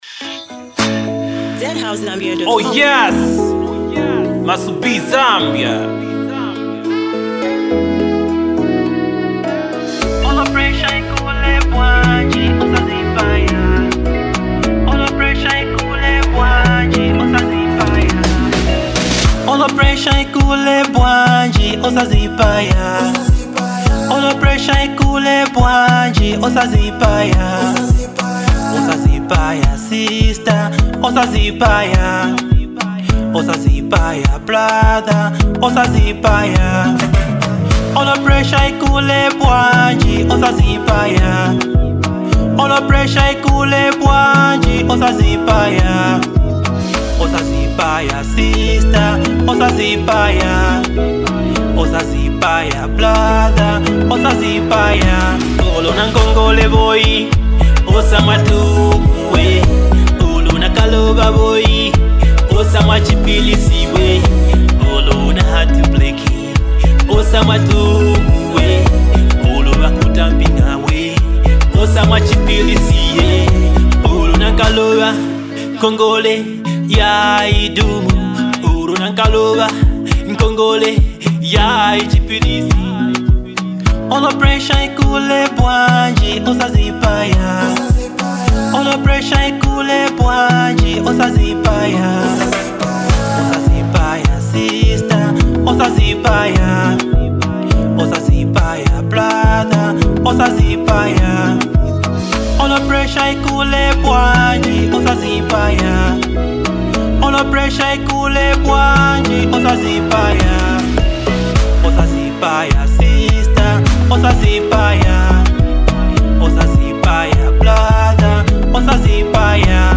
A motivational anthem for anyone fighting silent battles.